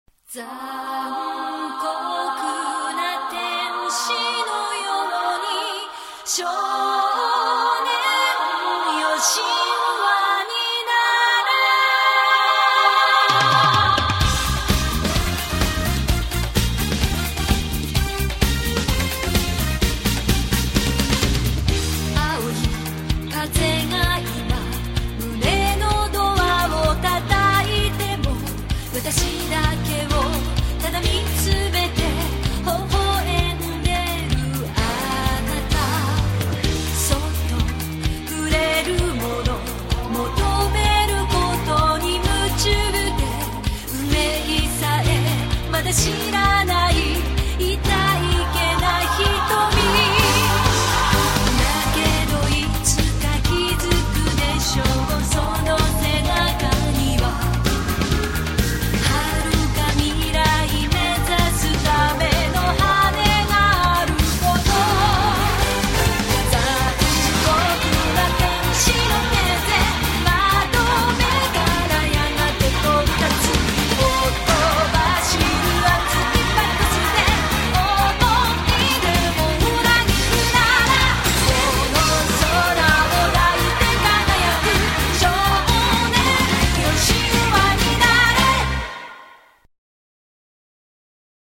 поп-бэнгер
опенинг